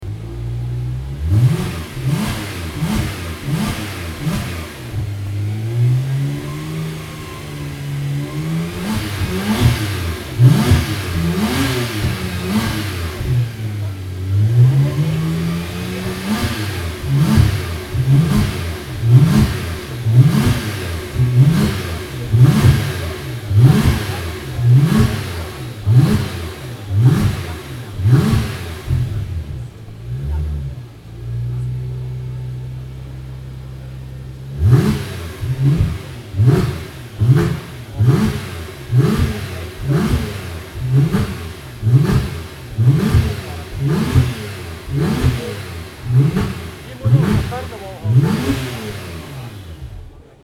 Porsche Sound Night 2017 - a loud night at the museum (Event Articles)